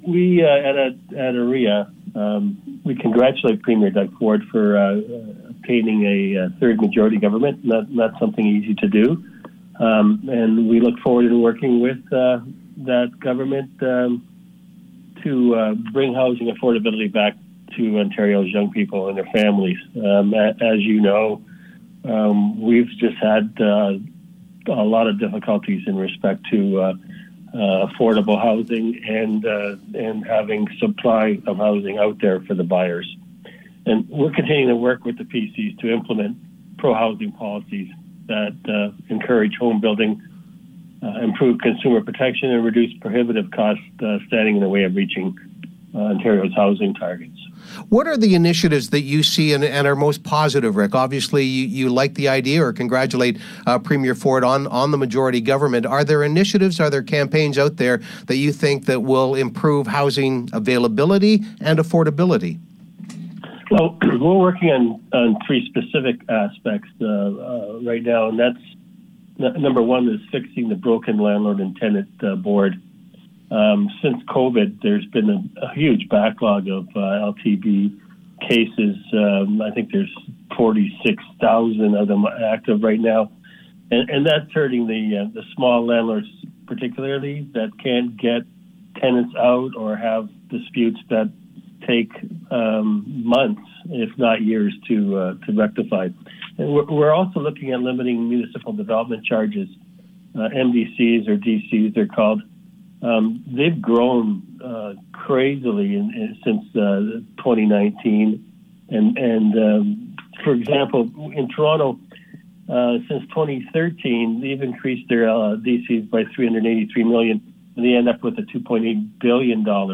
was a guest on a recent edition of Home Base in 560 CFOS